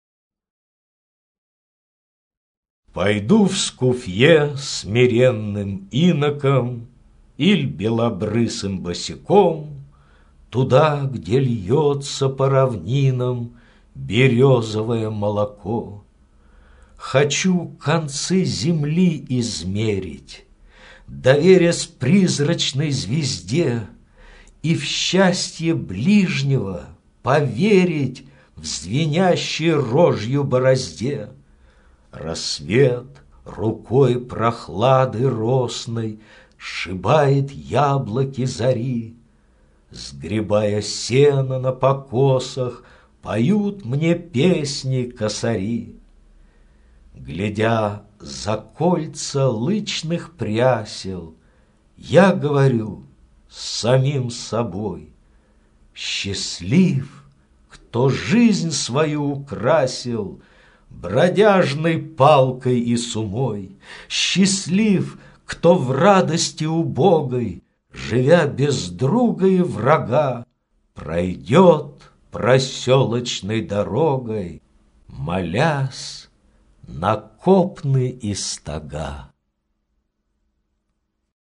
Прослушивание аудиозаписи стихотворения «Пойду в скуфье смиренным иноком...»  с сайта «Старое радио».